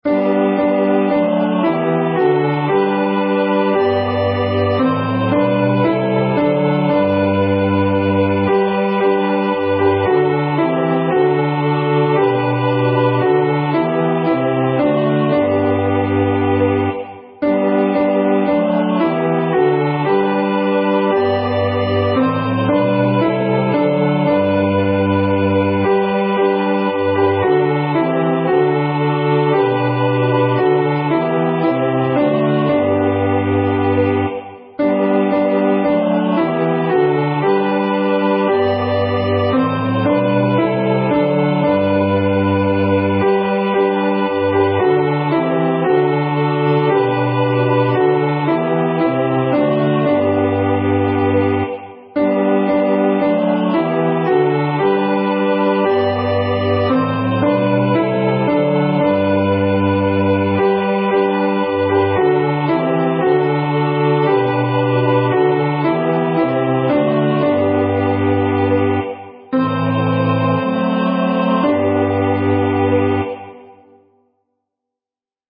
Genre: SacredMotet